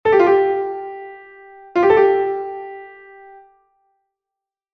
Grupeto de 3 notas
Un grupeto é de 3 notas cando o símbolo vai encima da nota ou vai entre dúas notas iguais.